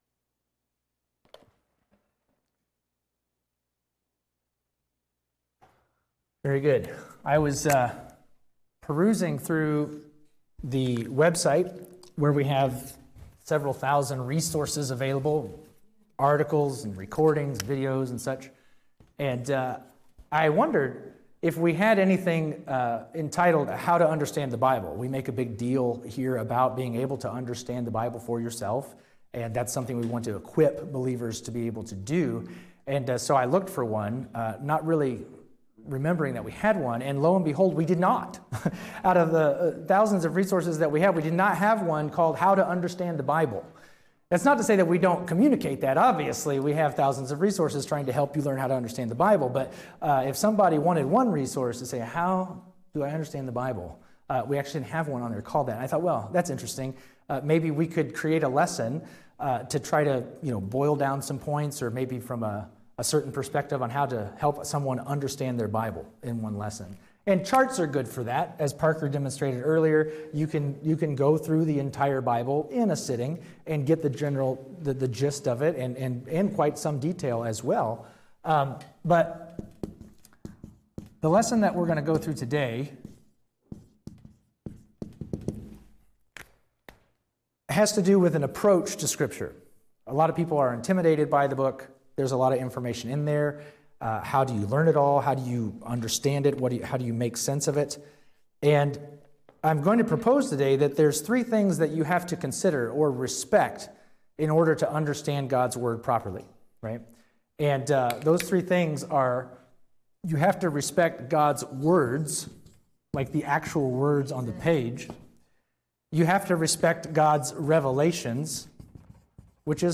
Download MP3 | Download Outline Description: True biblical understanding can only come when we respect God’s words, revelations, and purpose. This lesson explains how to do just that.